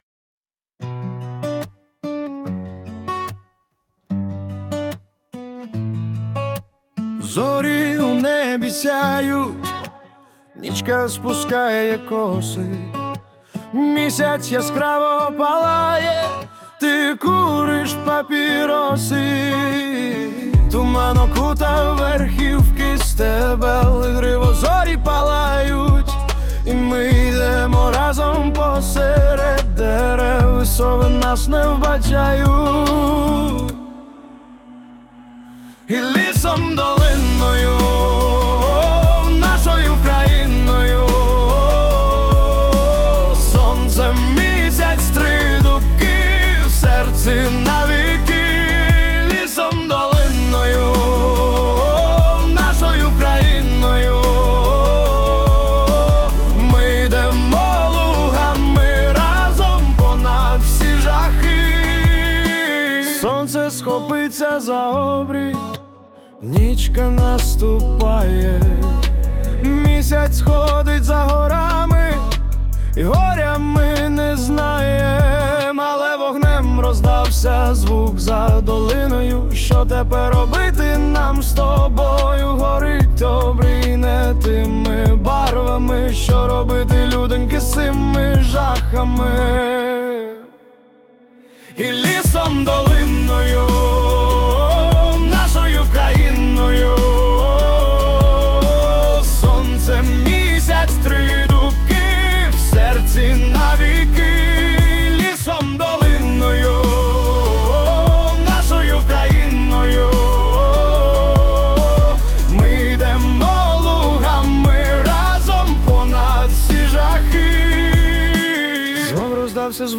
Стиль: Поп